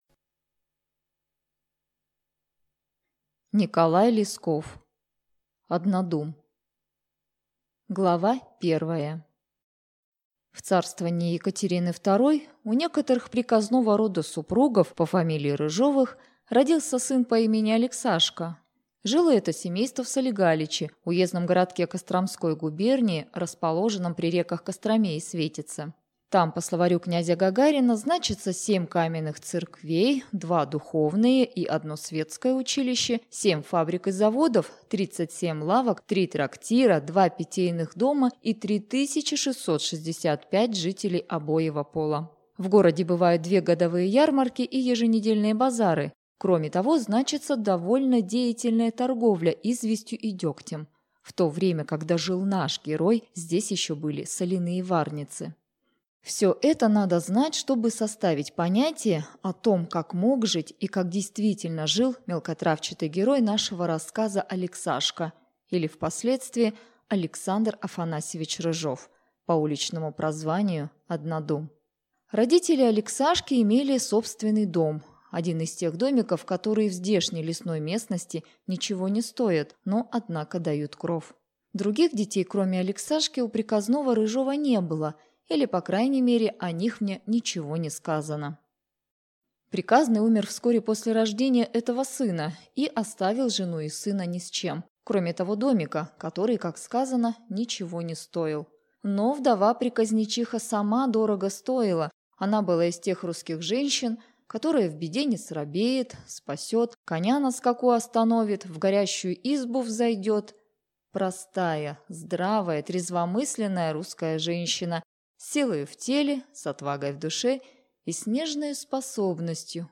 Аудиокнига Однодум | Библиотека аудиокниг